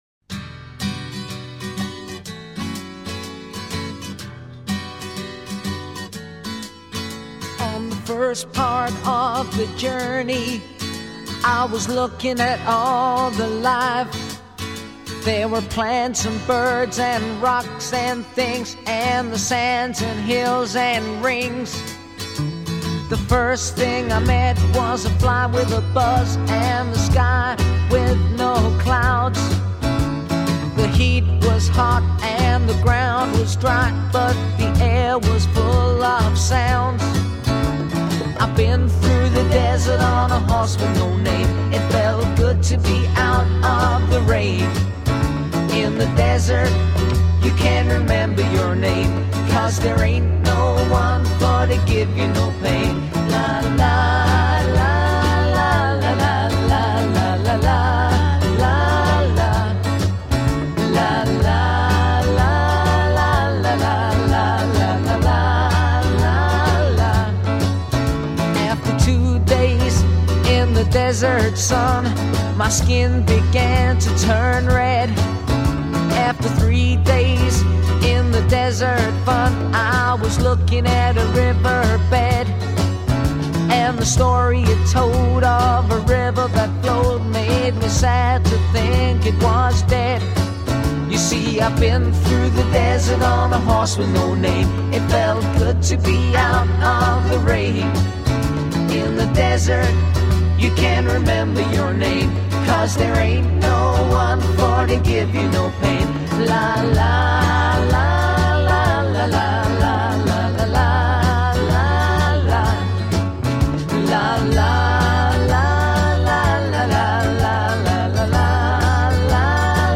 Nuestra agrupación toca las canciones de los grandes